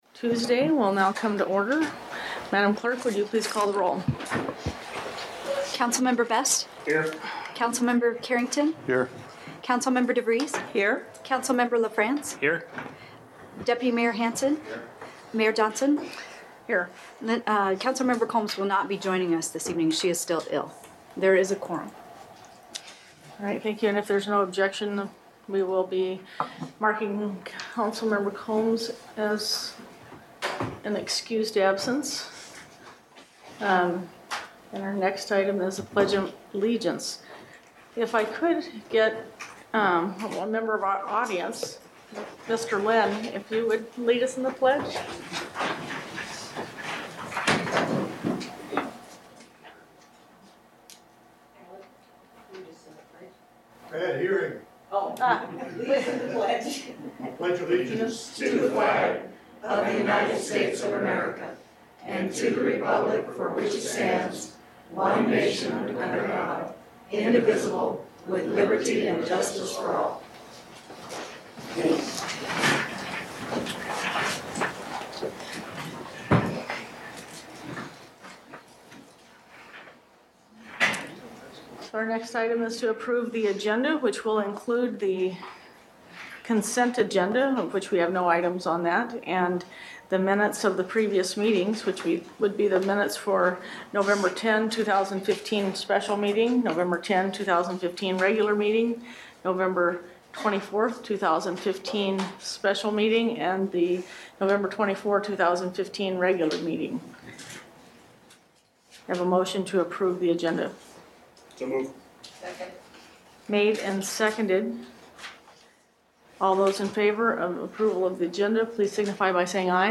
Palmer City Council Meeting 12.8.2015